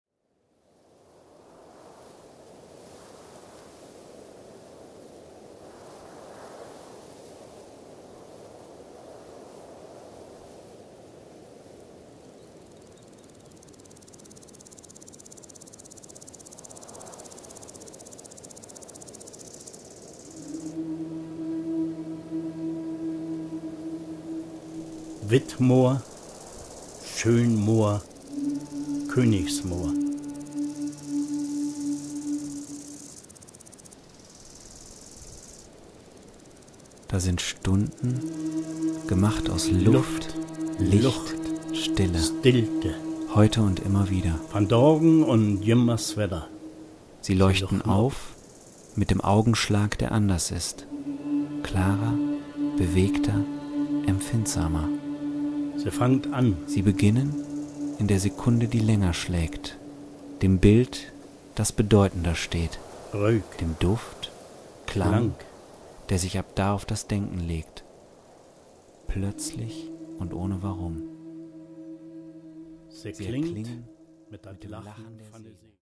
Drei symphonische Skizzen zum Moor.
5.1-Surround-Installation.
Und doch versteht sich das Stück als Ganzes weder als reine Landschaftsmalerei mit akustischen Mitteln noch als Musikstück, Hörspiel oder Soundscape.